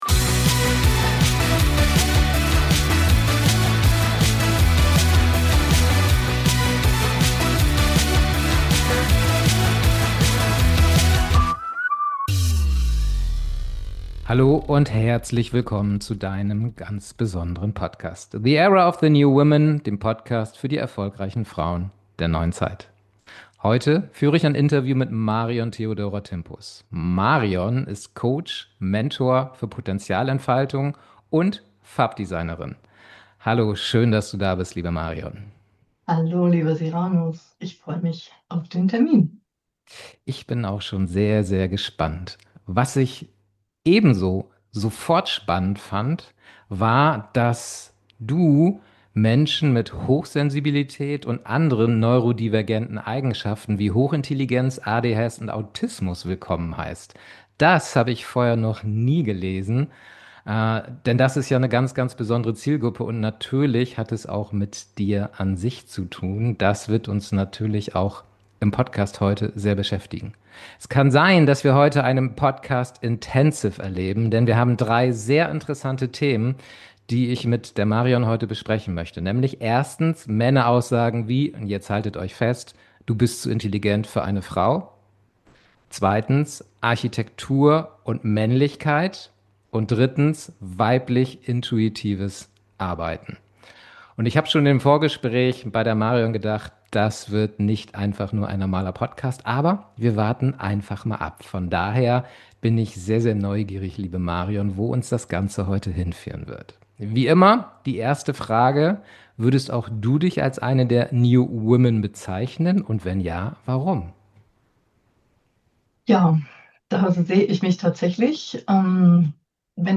Willkommen in der Welt hochsensibler, neurodivergenter Frauen. In dieser Folge erlebst Du ein Gespräch, das nicht „Interview“ genannt werden darf.